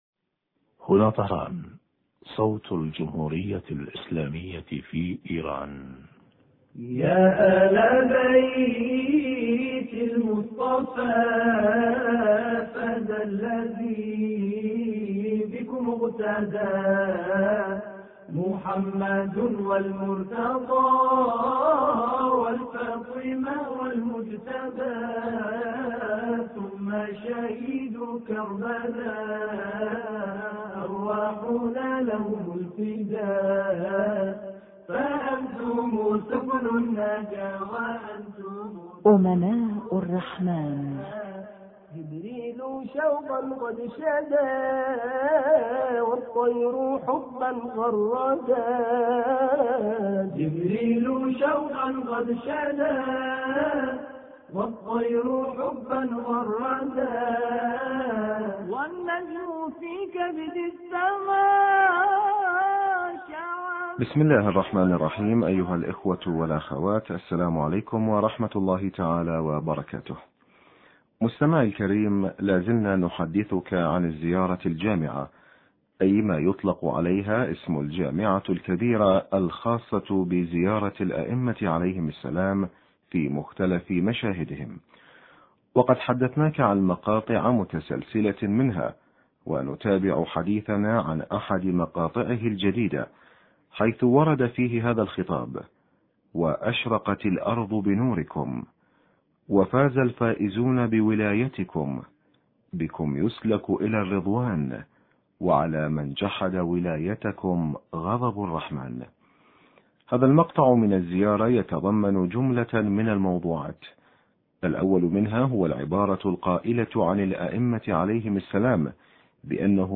شرح فقرة: وأشرقت الارض بنوركم وفاز الفائزون بولايتكم... حوار
الاتصال الهاتفي